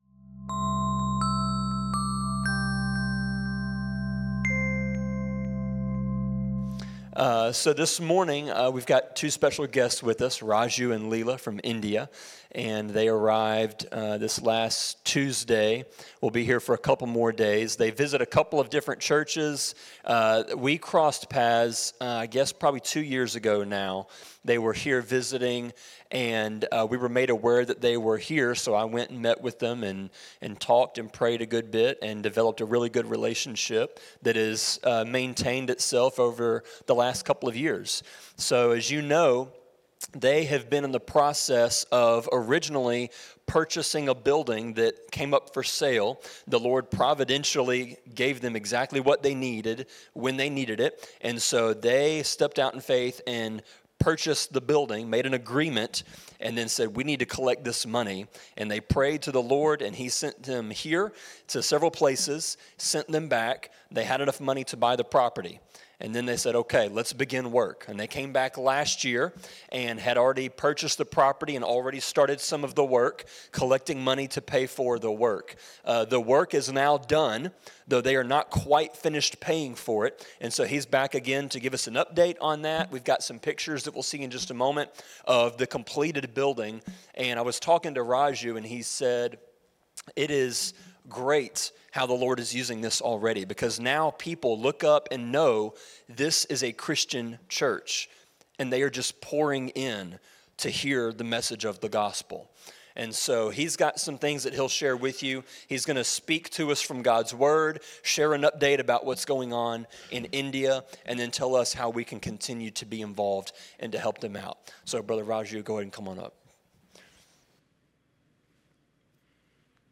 A sermon from one of our partners in India.
Sermon-24.6.9.m4a